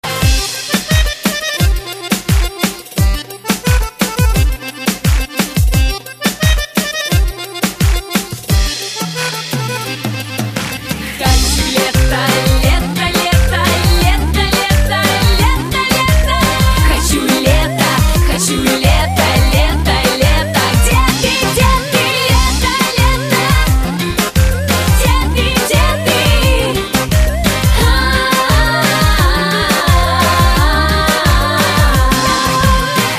громкие
женский вокал
веселые
заводные
dance
аккордеон
шуточные